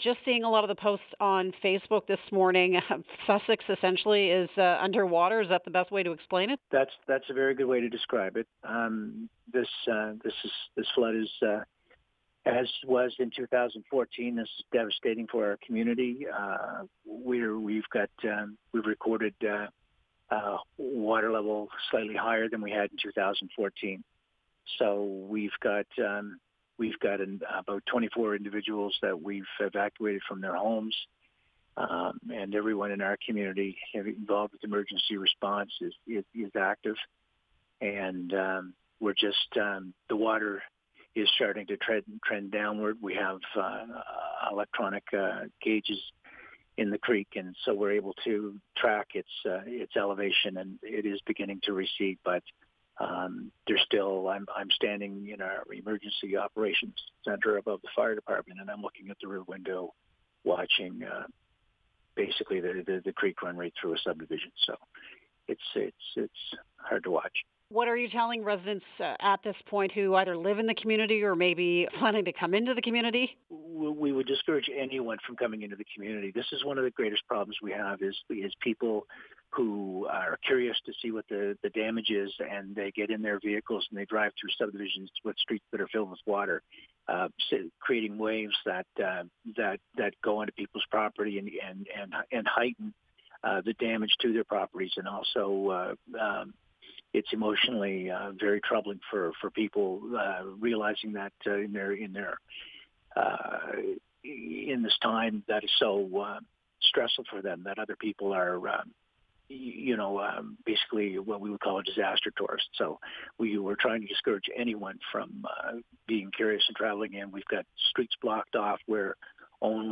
You can listen to the full interview with Marc Thorne below:
sussex-mayor-marc-thorne.mp3